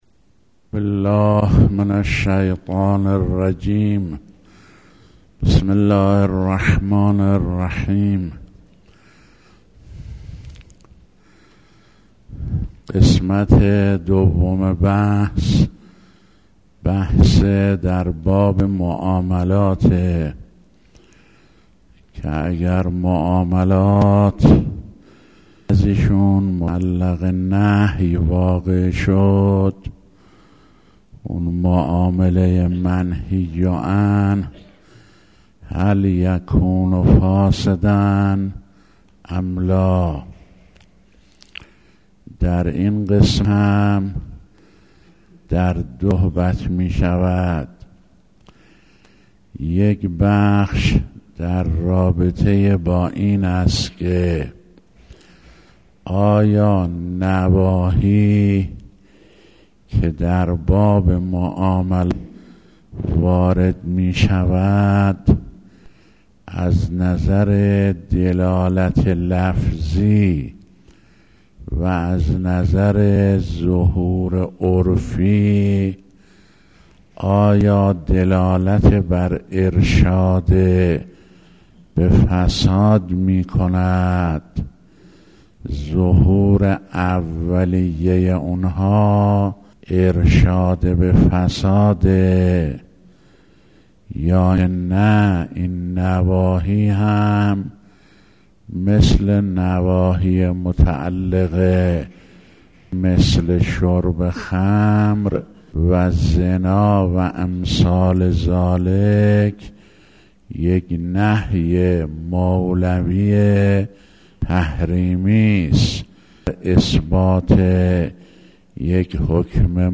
آيت الله فاضل لنکراني - اصول فقه | مرجع دانلود دروس صوتی حوزه علمیه دفتر تبلیغات اسلامی قم- بیان